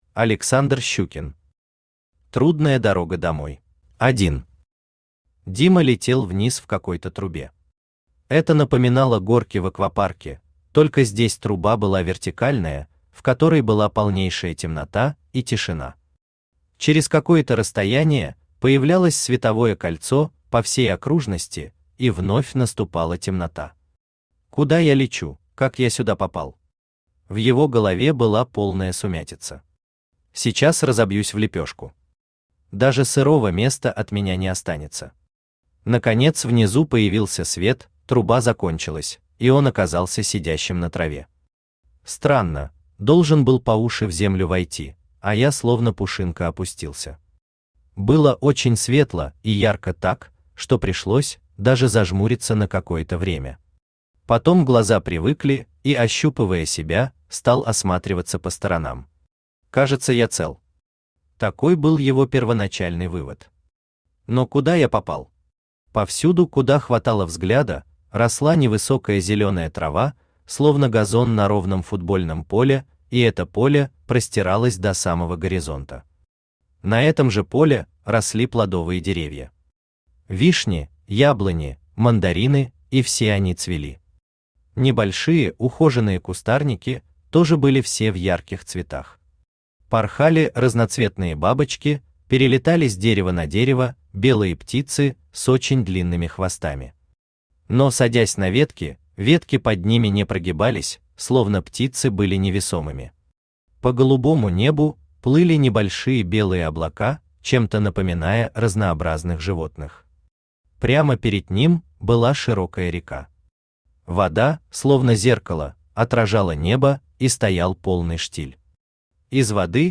Аудиокнига Трудная дорога домой | Библиотека аудиокниг
Aудиокнига Трудная дорога домой Автор Александр Щукин Читает аудиокнигу Авточтец ЛитРес.